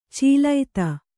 ♪ cīlaita